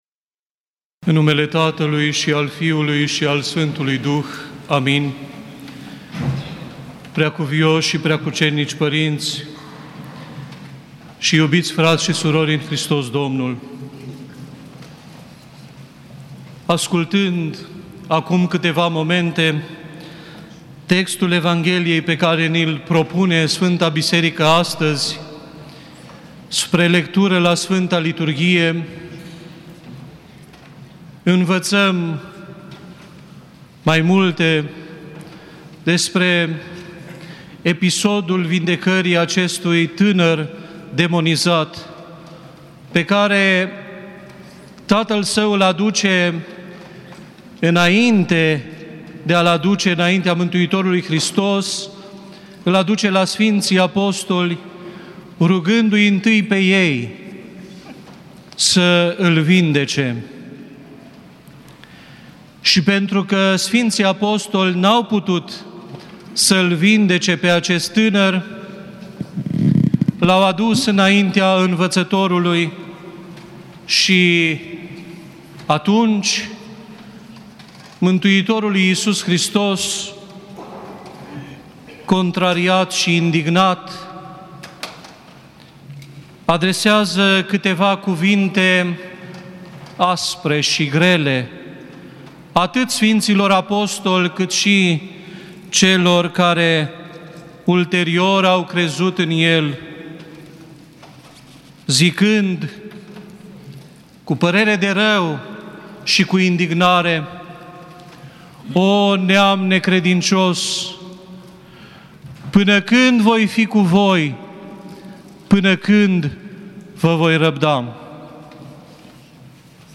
Predică la Duminica a 4-a din Postul Mare (a Sf.